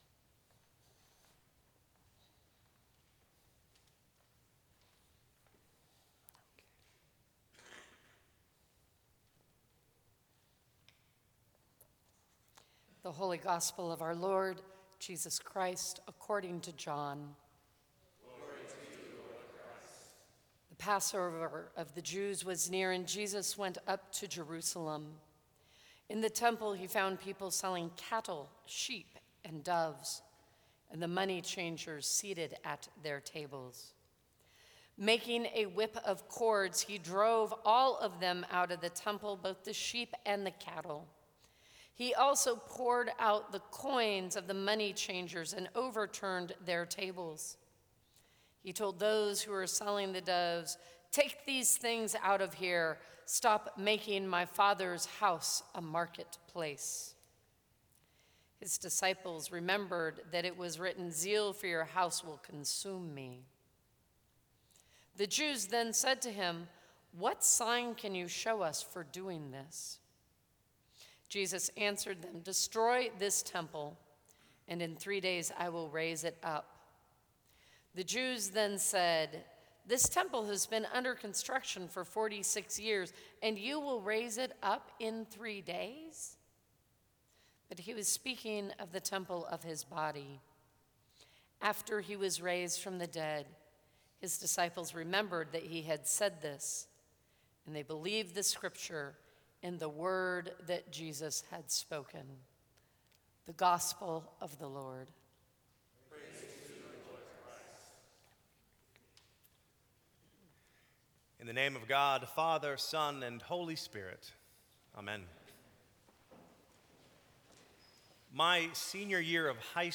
Sermons from St. Cross Episcopal Church Jesus: The New Temple Sep 25 2015 | 00:14:59 1x 00:00 / 00:14:59 Subscribe Share Apple Podcasts Spotify Overcast RSS Feed Share Link Embed